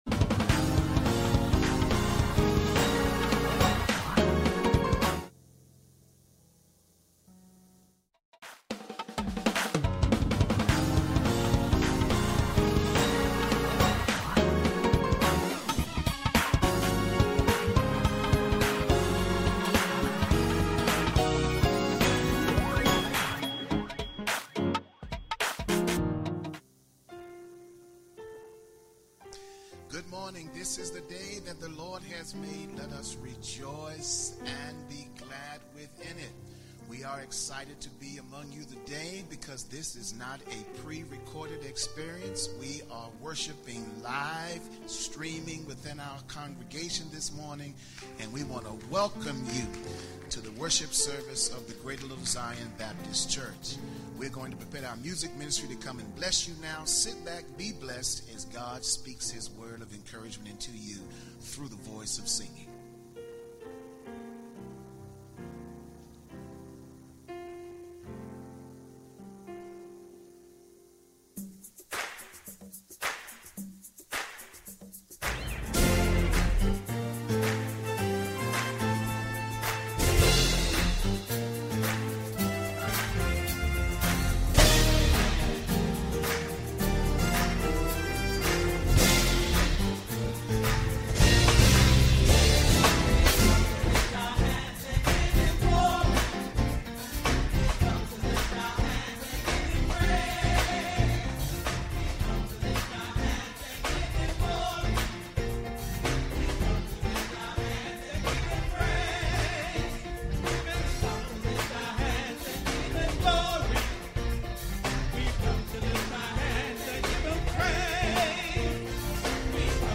Greater Little Zion Baptist Church Sermons